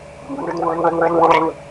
Gargle Sound Effect
Download a high-quality gargle sound effect.
gargle.mp3